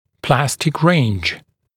[‘plæstɪk reɪnʤ][‘плэстик рэйндж]зона пластичности (дуги)